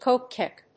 /kóʊpek(米国英語), kˈəʊpek(英国英語)/